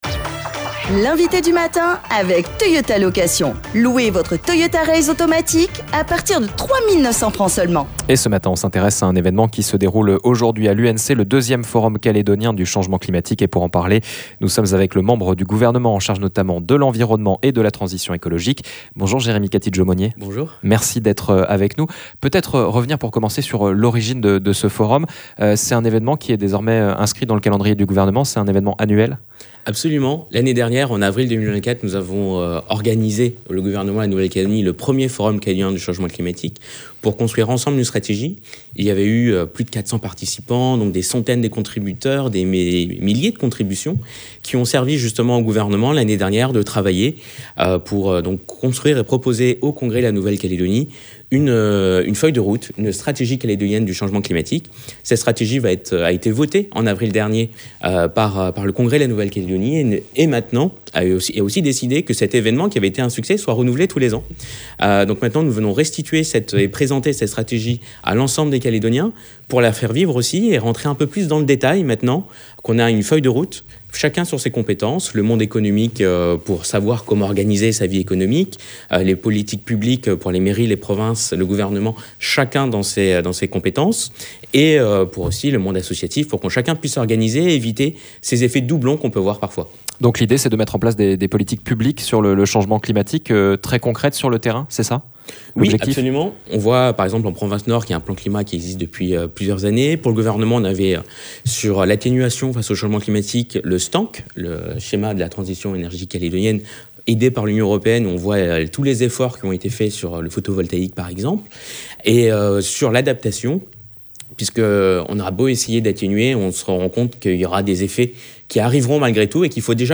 Il doit permettre de faire le point sur les avancées de la stratégie calédonienne du changement climatique, de présenter des projets locaux de recherche et d’adaptation et de valoriser les initiatives menées par les jeunes générations. Un sujet dont nous avons parlé avec Jérémie Katidjo-Monnier, membre du gouvernement en charge notamment de l’environnement et de la transition écologique.